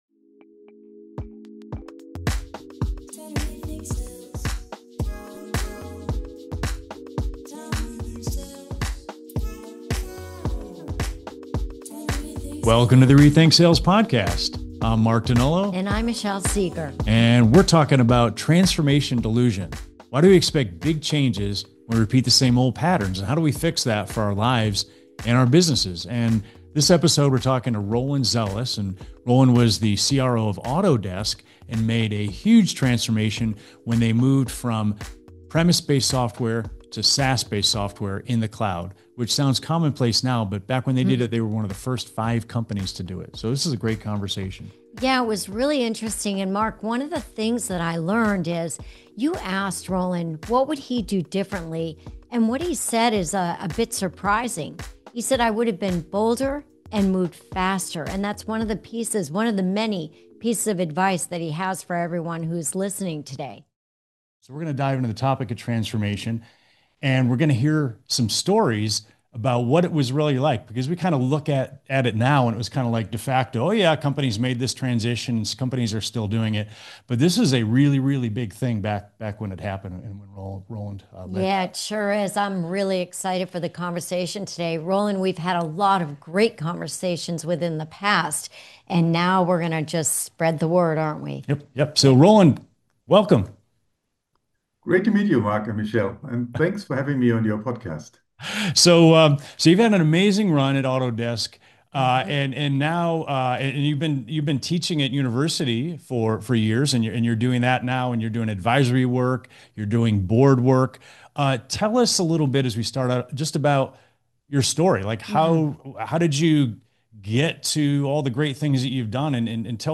In this two-part conversation